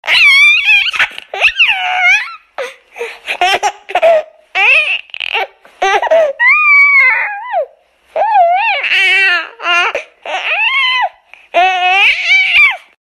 (tarzan 1999) Baby Tarzan’s Giggle